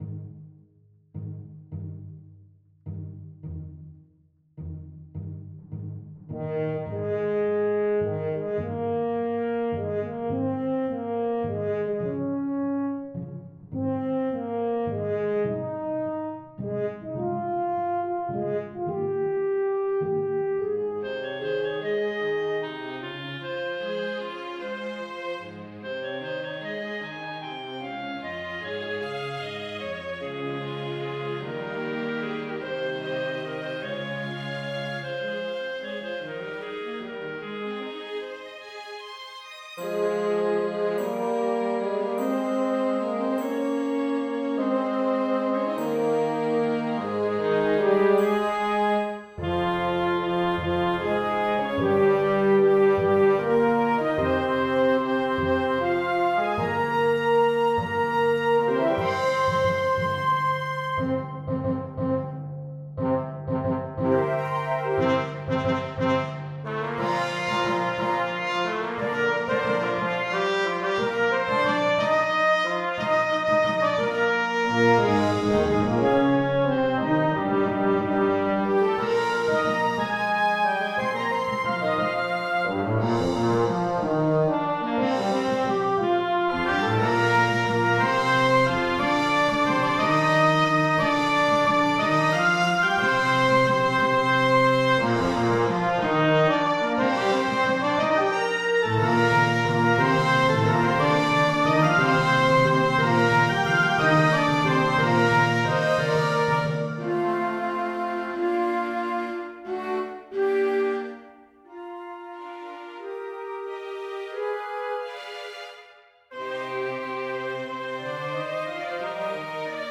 2020 Orchestral composition Heroic_Composition_in_Eb_Major_orchestra6 Download audio